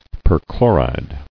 [per·chlo·ride]